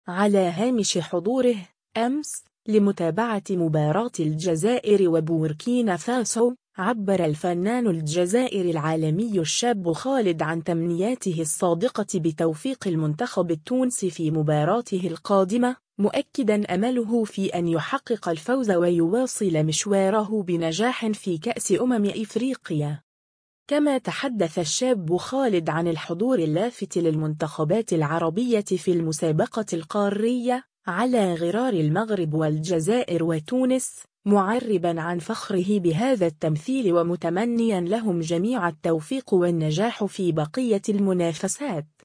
على هامش حضوره، أمس، لمتابعة مباراة الجزائر وبوركينا فاسو، عبّر الفنان الجزائري العالمي الشاب خالد عن تمنياته الصادقة بتوفيق المنتخب التونسي في مباراته القادمة، مؤكّدًا أمله في أن يحقق الفوز و يواصل مشواره بنجاح في كأس أمم إفريقيا.